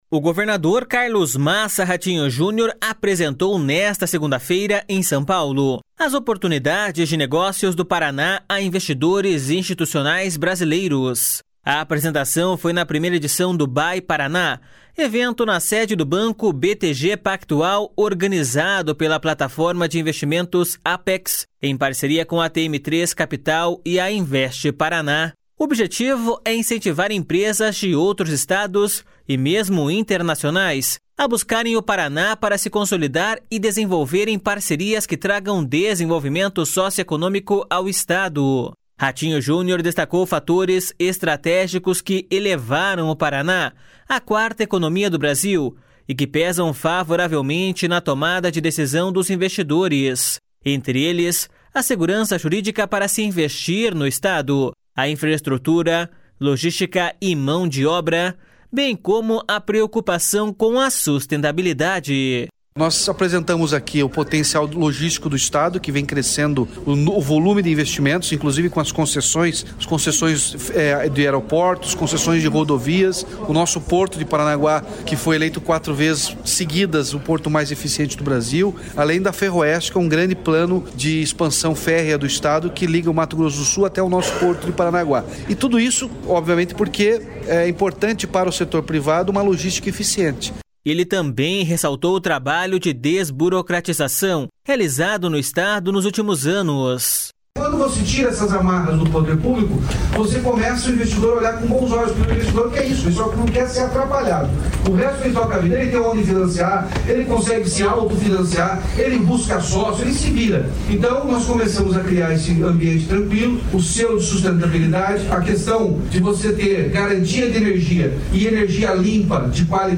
Entre eles, a segurança jurídica para se investir no Estado, a infraestrutura, logística e mão de obra, bem como a preocupação com a sustentabilidade.// SONORA RATINHO JUNIOR.//